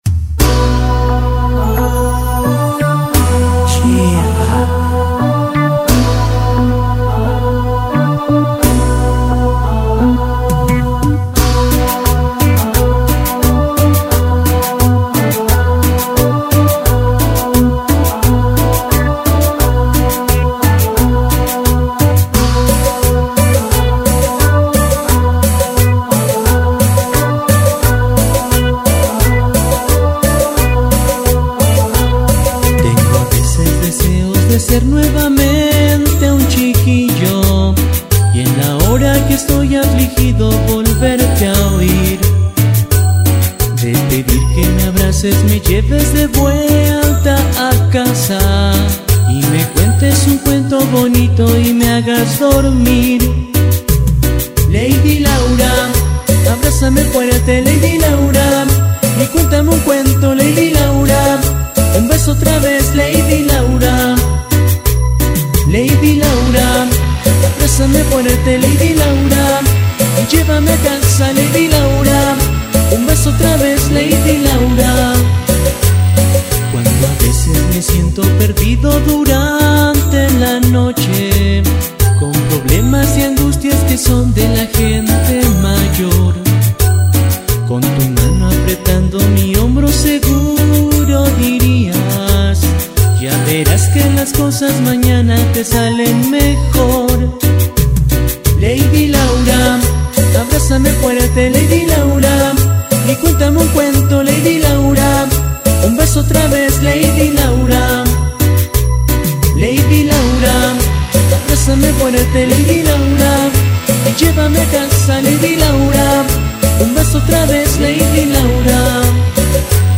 grupo musica andina